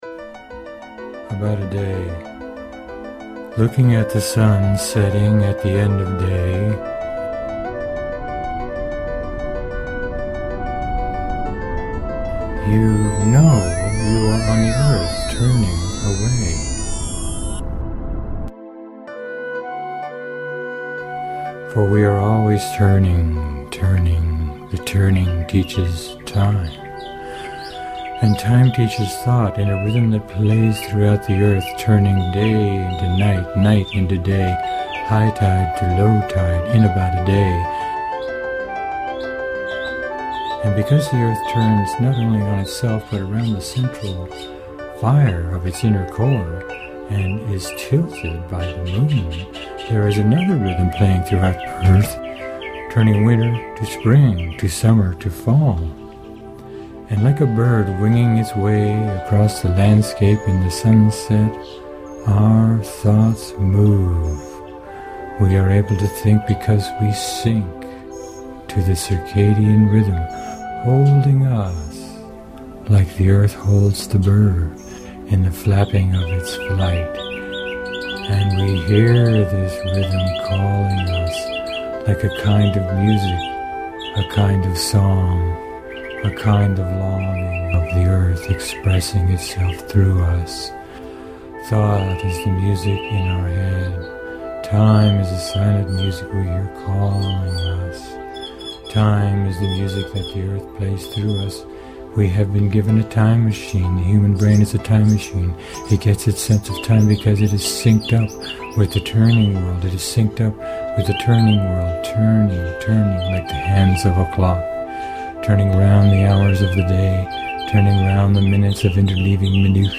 Sanskrit/English round chorus
Tuning in to the chamber music of the spheres
Spoken Word with Ambient Chill music,